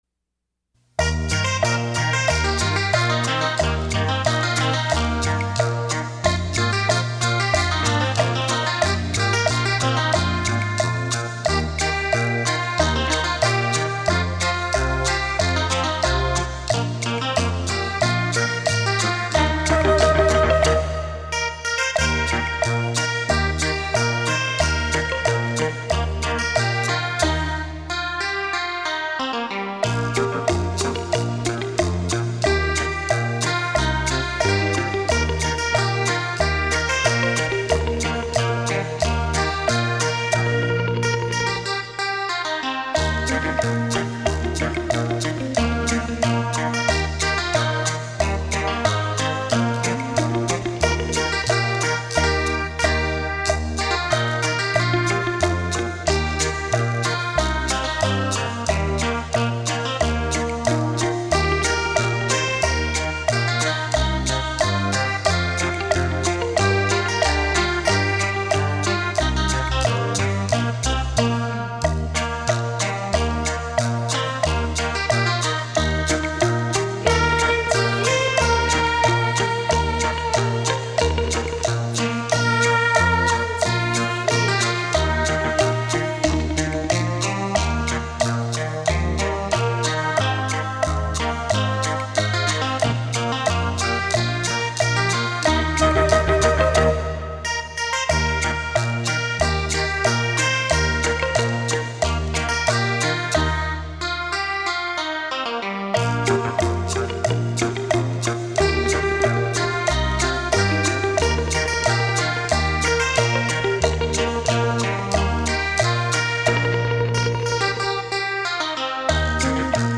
汽车音响测试碟
立体音声 环绕效果
音响测试专业DEMO碟 让您仿如置身现场的震撼感受